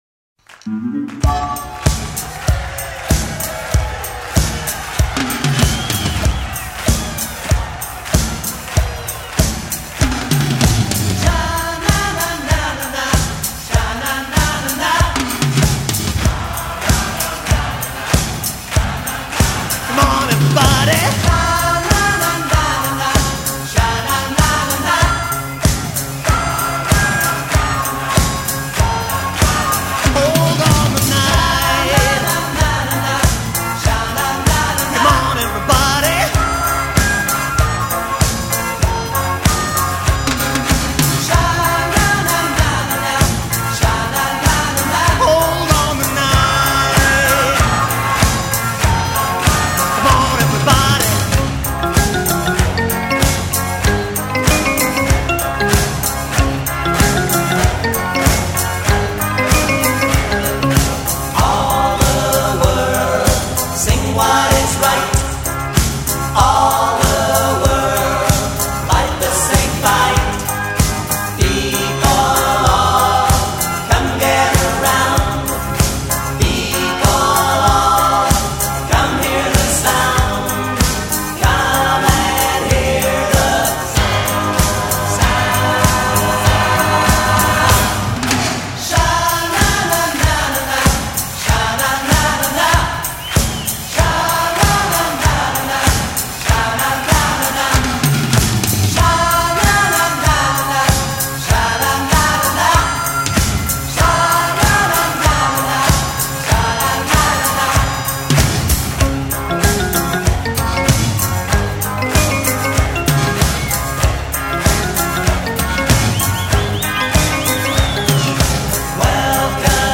Mille merci pour ce générique !